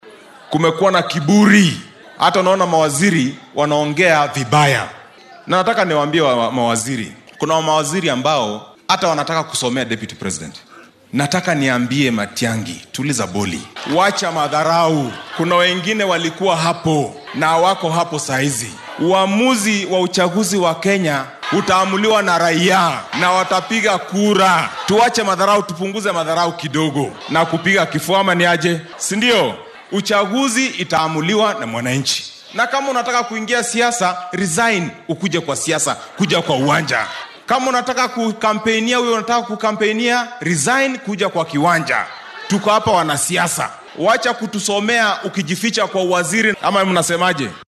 DHAGEYSO:Xildhibaanka Langata oo ka jawaabay hadal uu jeediyay wasiirka arrimaha gudaha